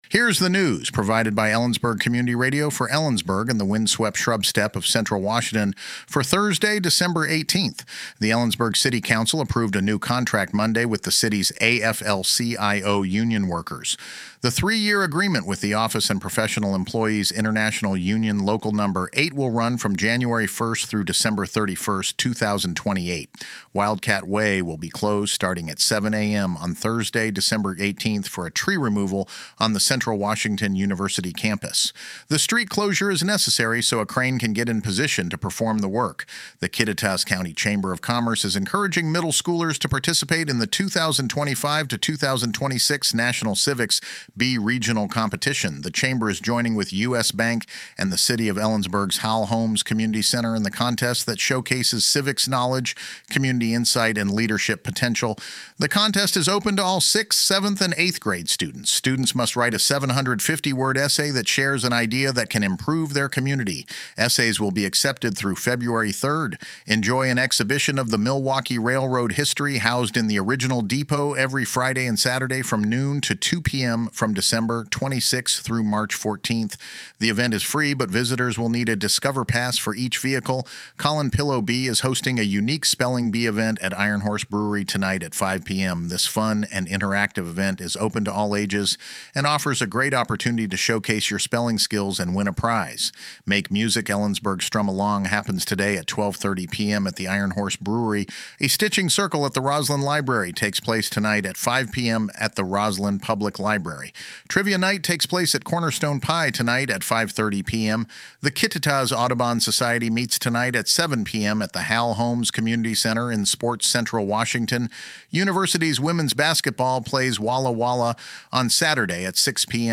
LISTEN TO THE NEWS HERE NEWS The Ellensburg City Council approved a new contract Monday with the city’s AFL-CIO union workers.